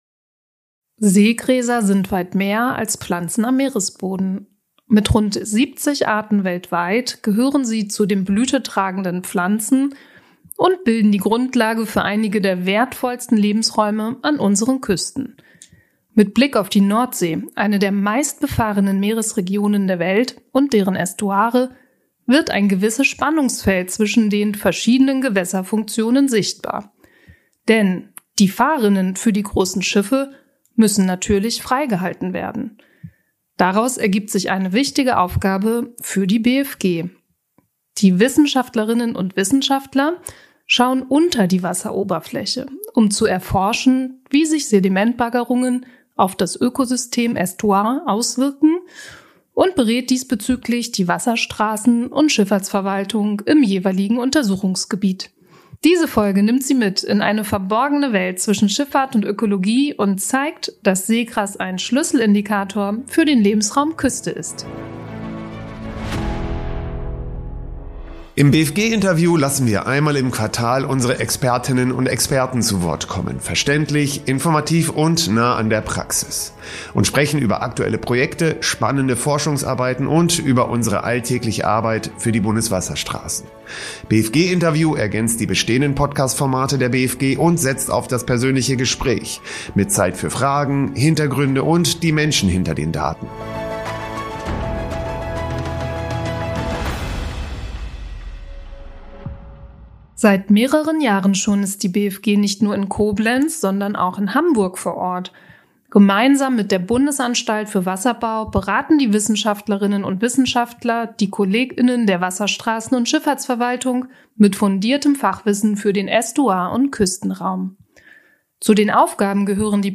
Der BfG-Podcast „BfG Interview“ wird in Koblenz von der Bundesanstalt für Gewässerkunde produziert.